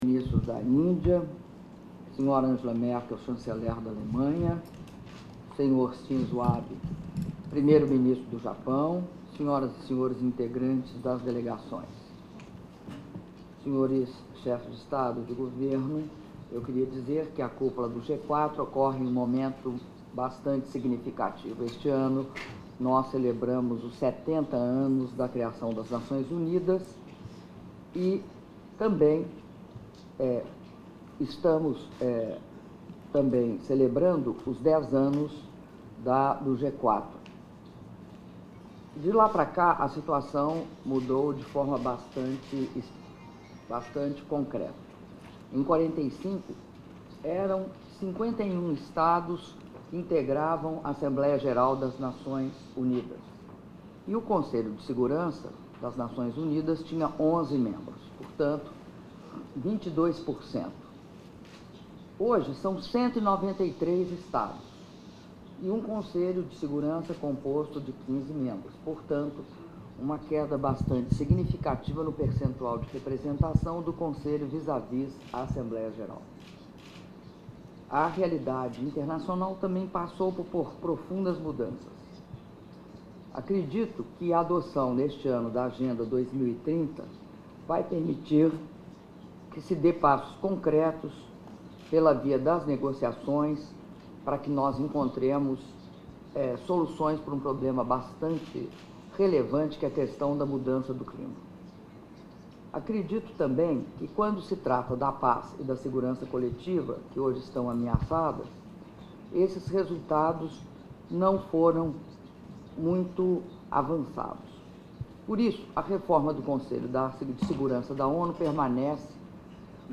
Áudio do discurso da Presidenta da República, Dilma Rousseff, durante Reunião de Cúpula do G4 - Nova Iorque/EUA (2min57s)